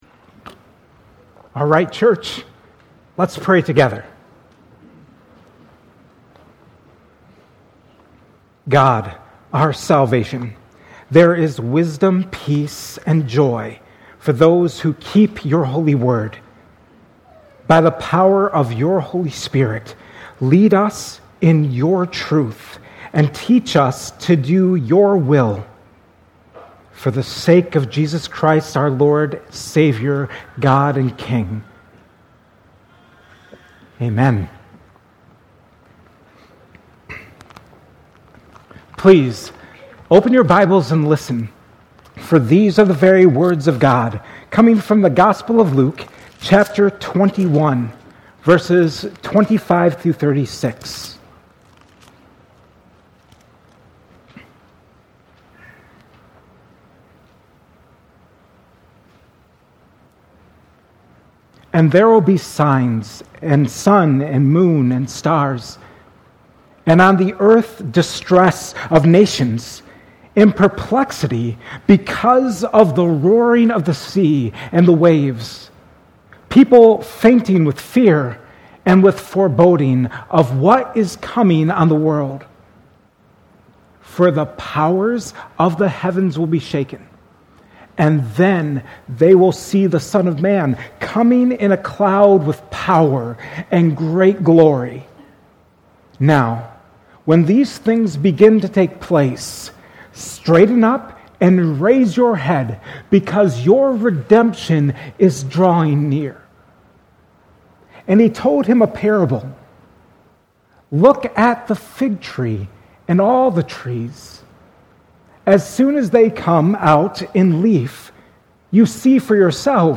at Cornerstone Church in Pella.